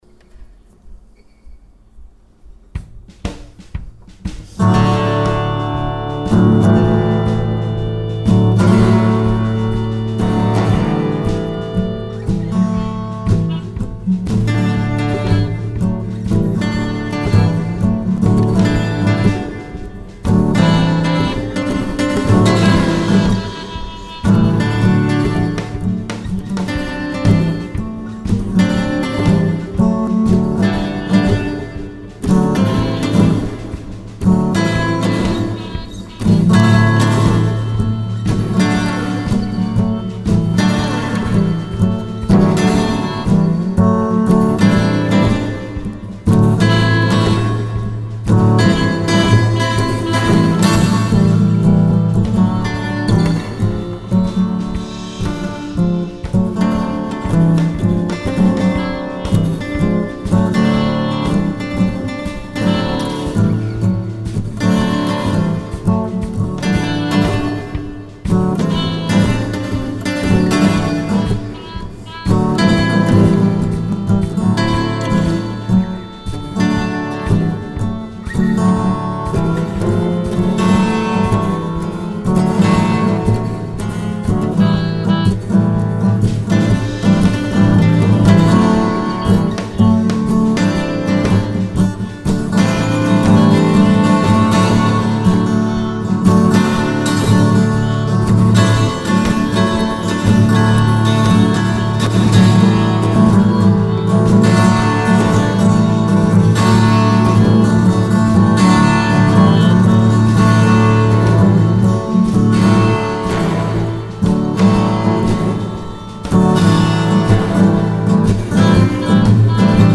This piece is a work in progress.